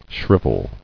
[shriv·el]